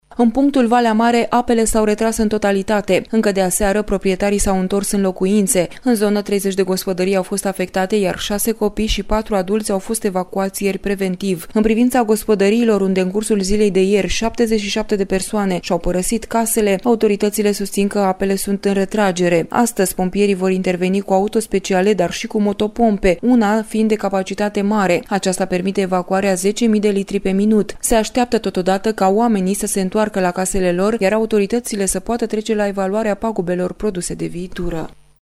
Relatează corespondent al RRA